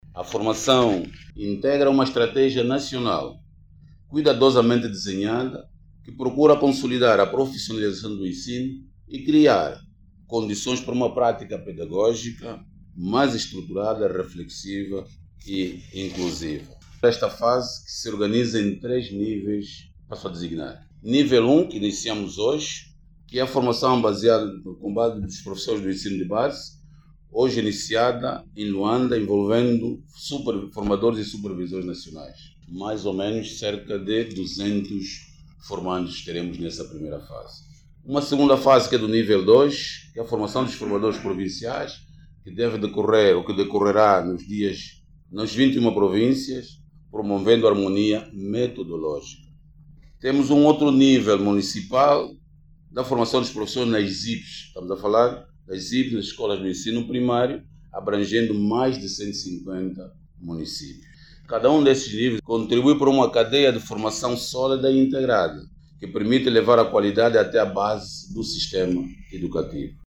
Mais de 27 mil professores e gestores escolares participam de uma acção formativa, sobre os modelos de transmissão de conhecimentos aos estudantes nas classes de base. Trata-se de um projecto que conta com o financiamento do Banco Mundial, e implementado pelo Instituto Nacional de Quadros do Ministério da Educação. O Director do INQ, Caetano Domingos diz que a acção formativa é mais um momento de consolidação da estratégia de profissionalização no ensino.